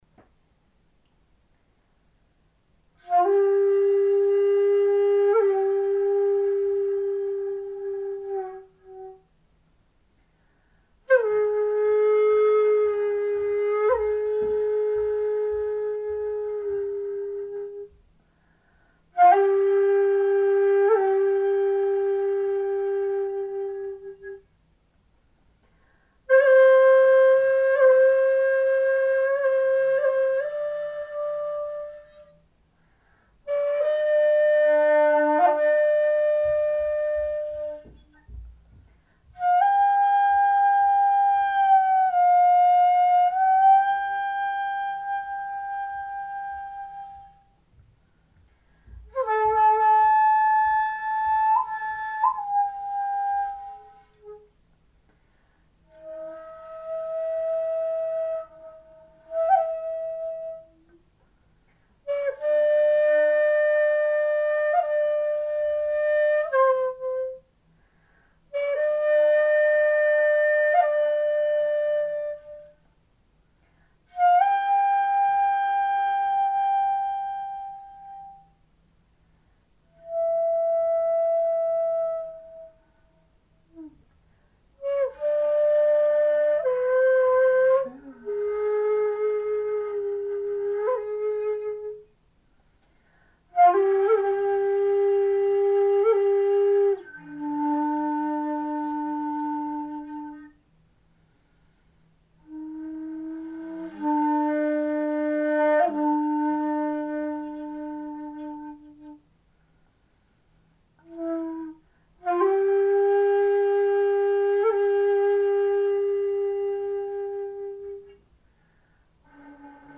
Это рядом с Японией ;-) Но, наверное, я единственный человек на всём острове, который играет на сякухати.